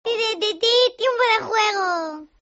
Los sonidos del maquinillo